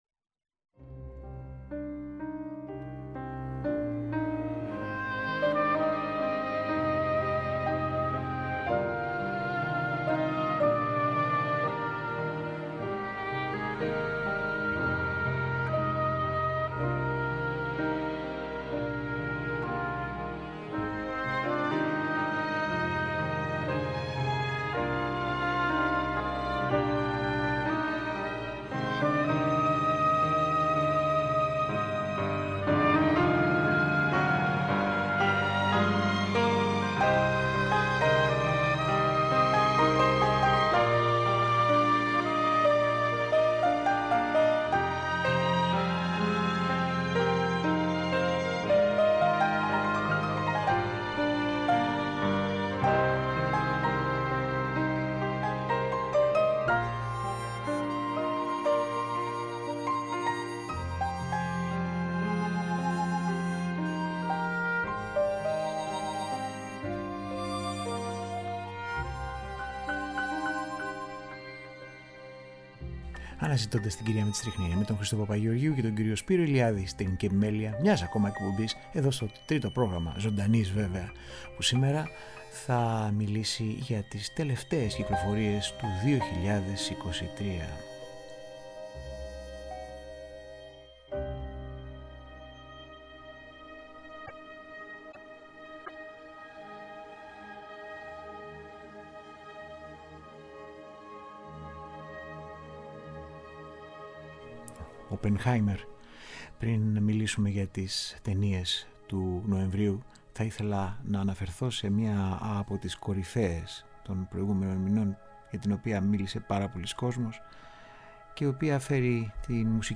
Μουσικές από κινηματογραφικές κυκλοφορίες του Νοεμβρίου – μέρος 1ο